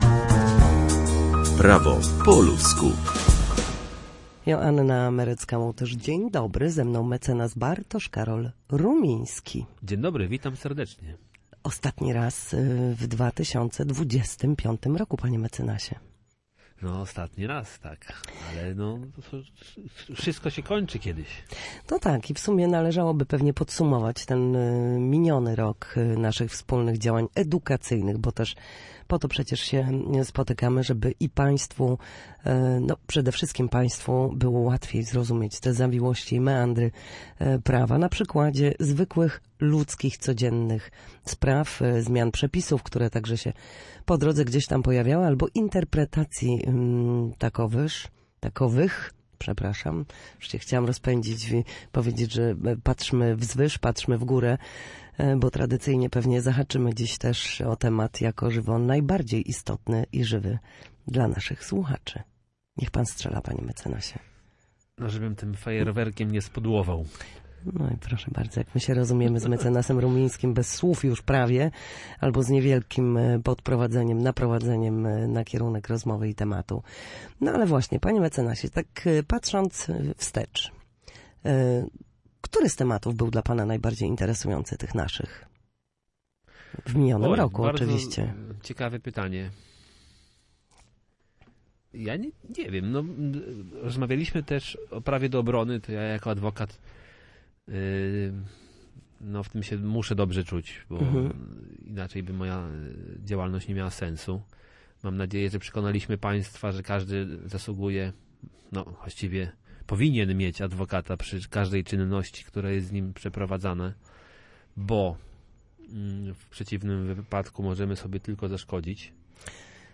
W każdy wtorek o godzinie 13:40 na antenie Studia Słupsk przybliżamy Państwu meandry prawa.
W naszym cyklu prawnym gościmy ekspertów, którzy odpowiadają na pytania związane z zachowaniem w sądzie lub podstawowymi zagadnieniami prawnymi.